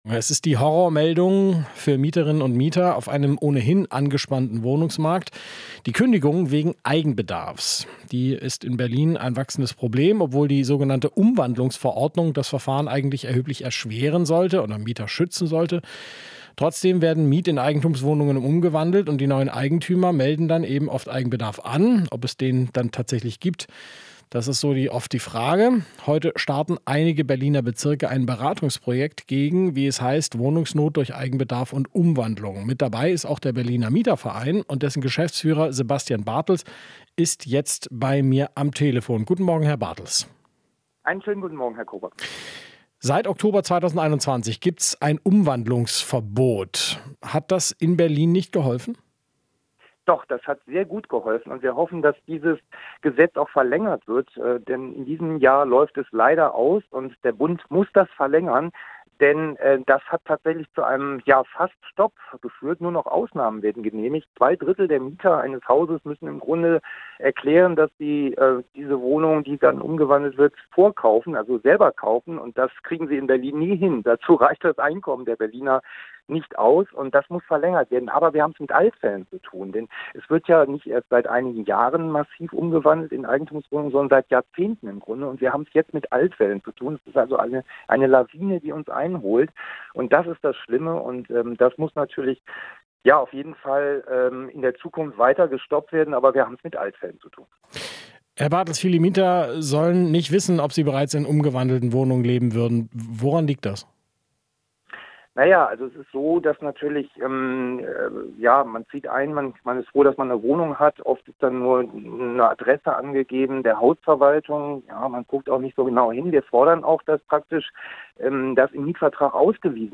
Interview - Mieterverein fordert mehr Schutz vor Eigenbedarfkündigung